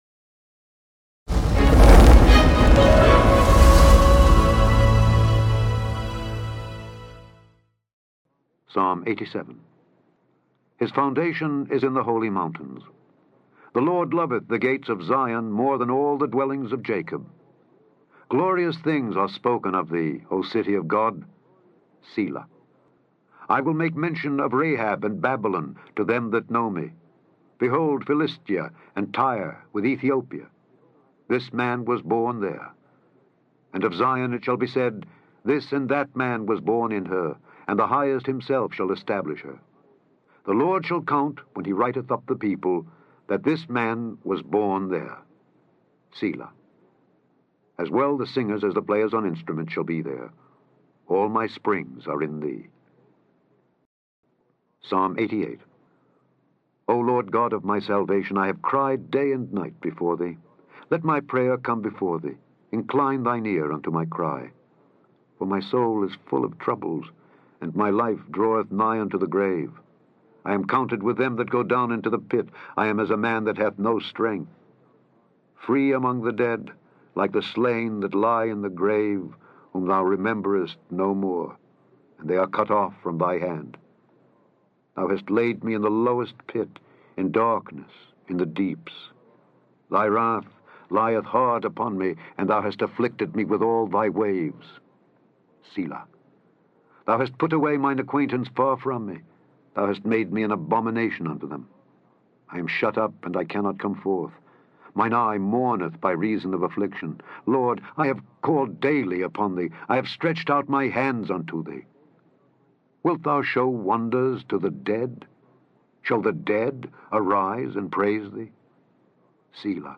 Daily Bible Reading: Psalms 87-89
In this podcast, you can listen to Alexander Scourby read Psalms 87-89.